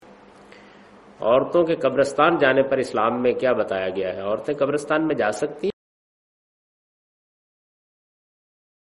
Javed Ahmad Ghamidi responds to the question 'Can women visit graves'?
جاوید احمد غامدی اس سوال کا جواب دہے رہے ہیں کہ "کیا خواتین قبرستان میں جاسکتی ہیں؟"